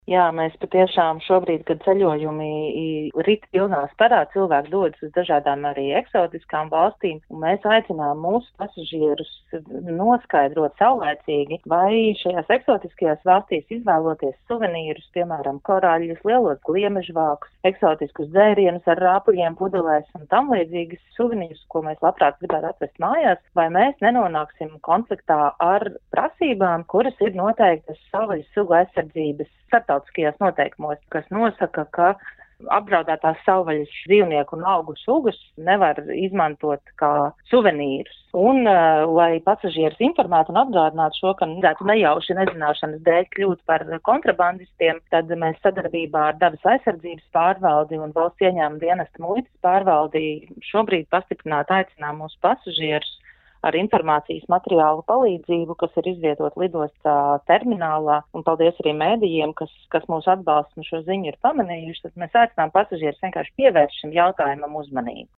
RADIO SKONTO Ziņās par akciju lidostā “Rīga”